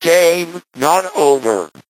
8bit_ulti_vo_01.ogg